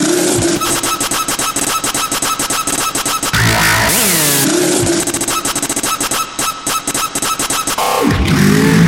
描述：节奏为108，所以它更适合Moombahton/dubstep. 享受
Tag: 108 bpm Dubstep Loops Bass Loops 1.50 MB wav Key : Unknown